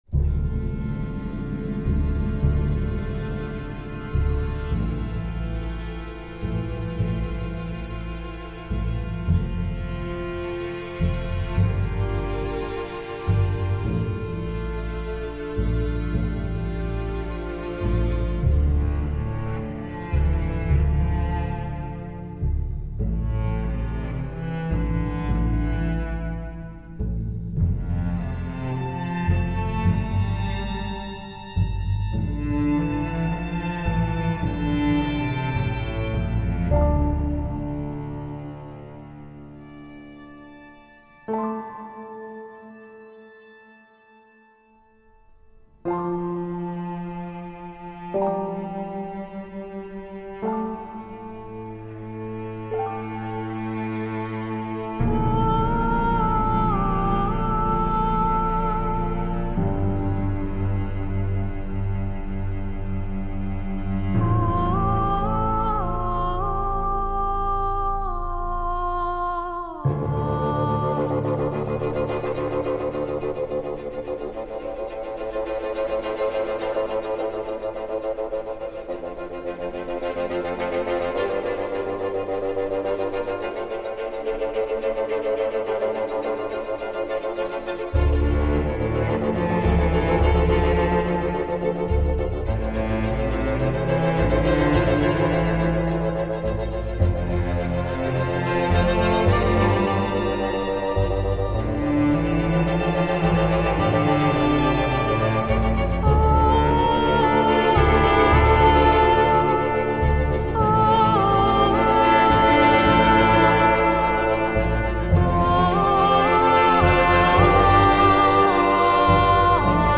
Piano, cordes et voix éthérées
Mélancolique, contemplatif et un peu répétitif.